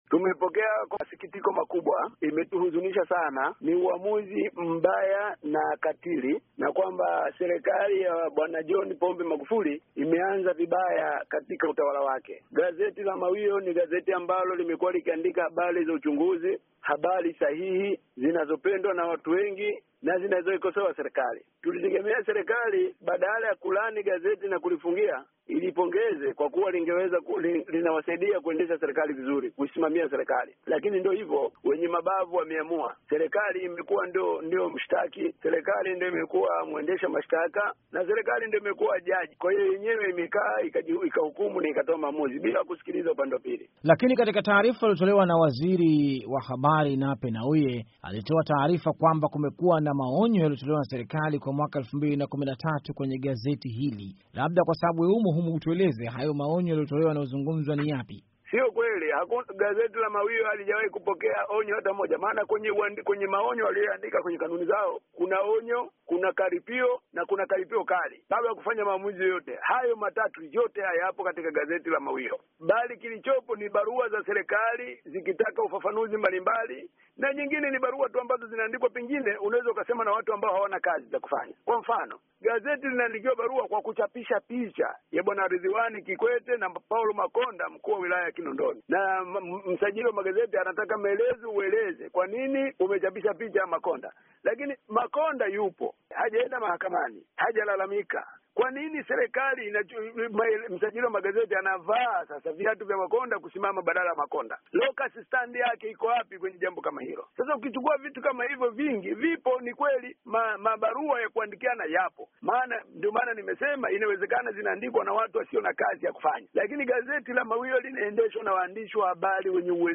Mahojiano na Said Kubenea